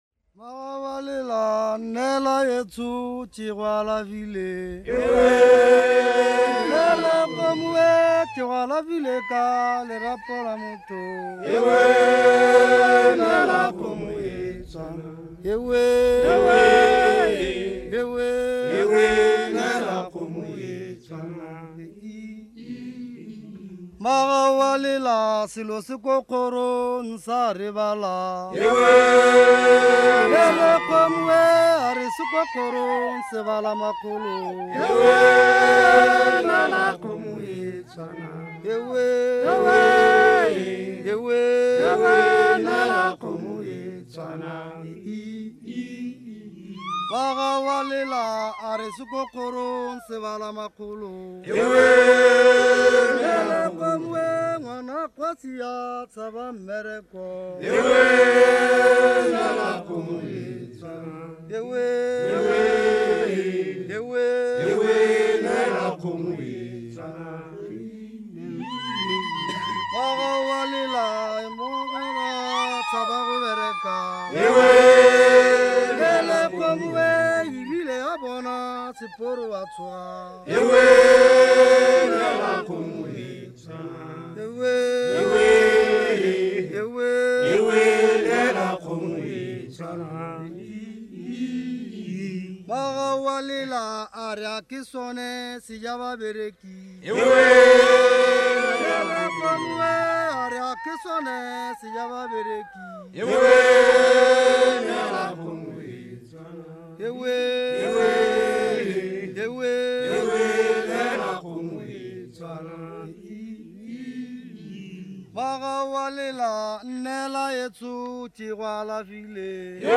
Folk songs, Tswana
Field recordings
sound recording-musical
Initiation song sung on returning from the hills